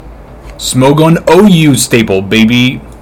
infinitefusion-e18/Audio/SE/Cries/FERROTHORN.mp3 at releases-April